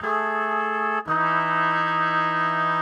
GS_MuteHorn_85-AE.wav